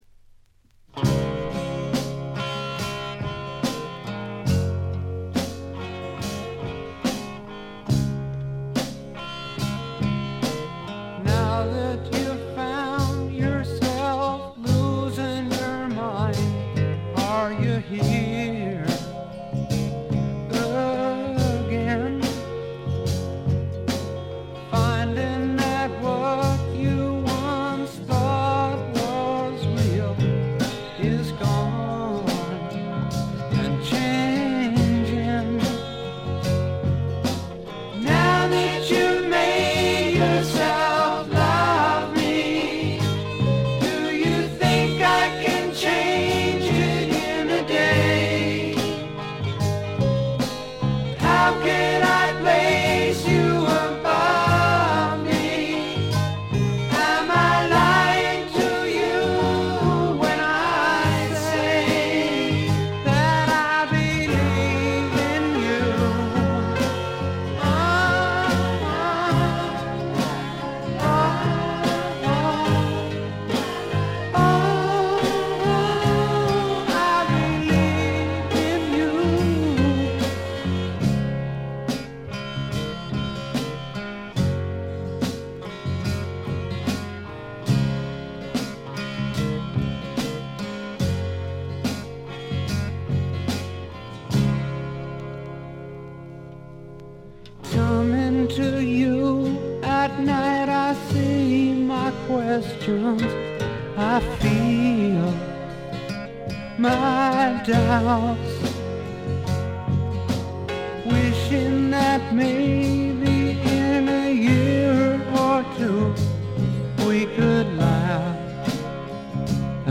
試聴曲は現品からの取り込み音源です。
guitar, piano, vibes, vocal
bass
drums, vocal